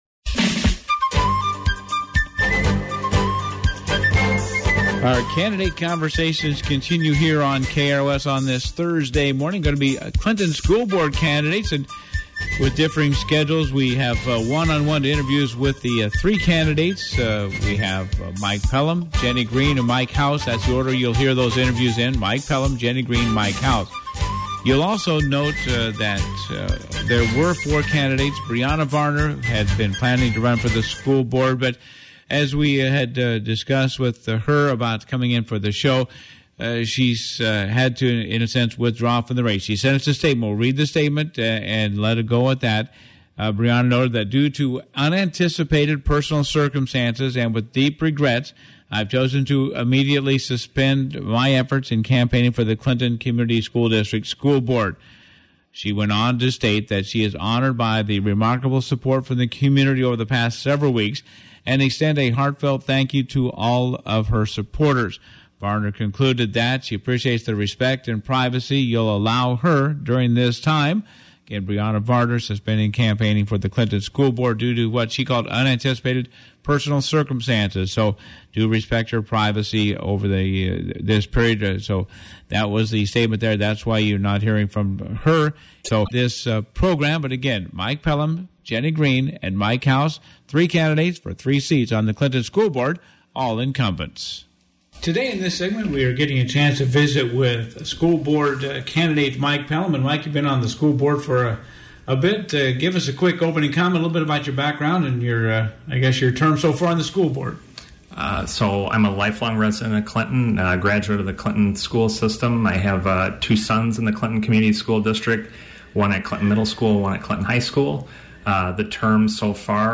There are 3 seats on up for election – if you missed the program on-the-air you can hear from the 3 incumbents seeking re-election. Mike Pelham, Jenny Green and Mike House are seeking to return to the school board.